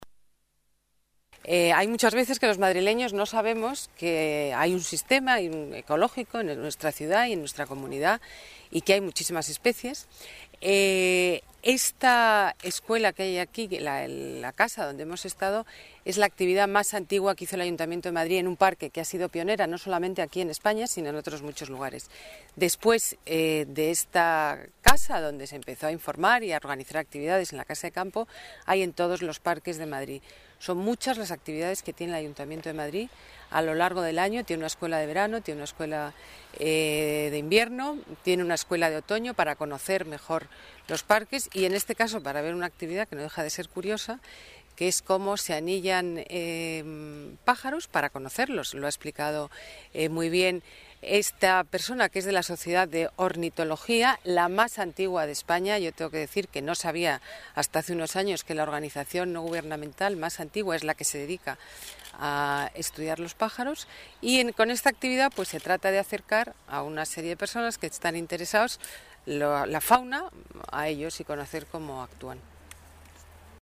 Botella asiste a una jornada de anillamiento científico, incluida en la Escuela de Verano 2009
Nueva ventana:Ana Botella, delegada de Medio Ambiente: anillamiento